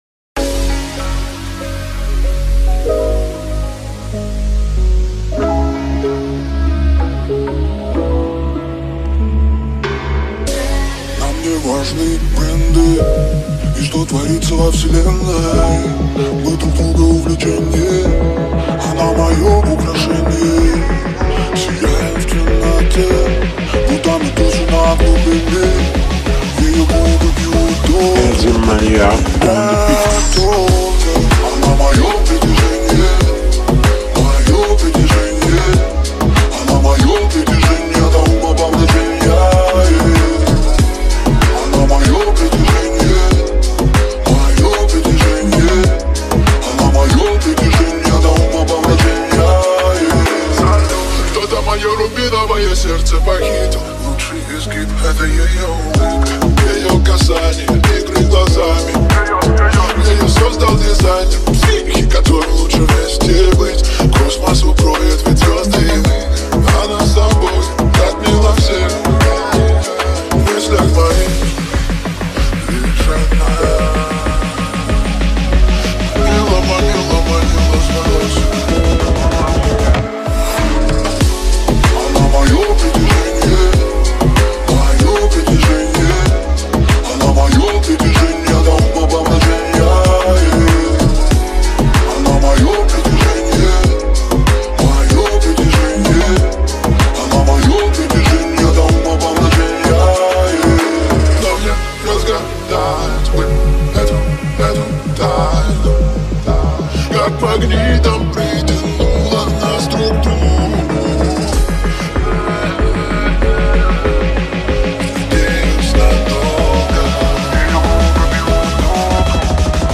• Категория: Русская музыка